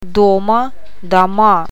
O ääntyy painottomana a :n kaltaisena, e ja я i :n tai ji :n tapaisena äänteenä.
Paino eri tavulla saa aikaan merkityseron: